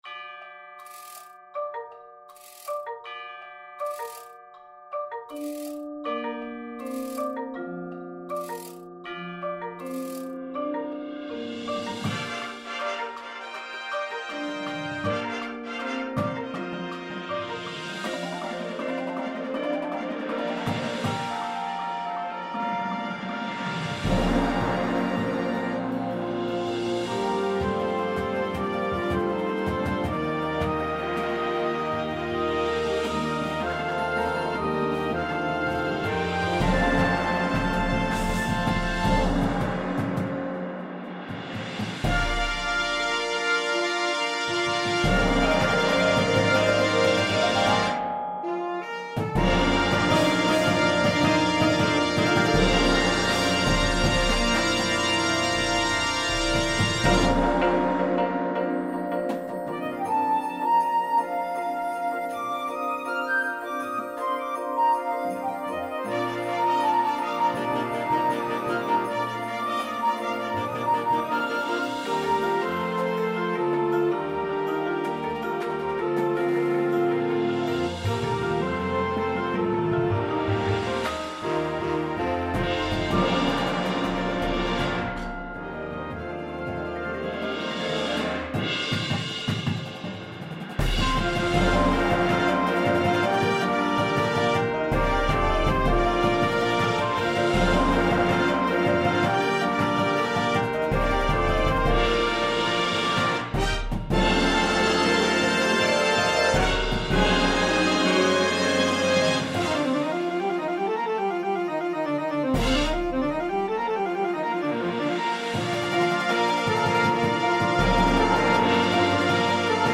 • Flute
• Clarinet 1, 2
• Alto Sax 1, 2
• Trumpet 1
• Horn in F
• Trombone 1, 2
• Tuba
• Snare Drum
• Bass Drums
• Front Ensemble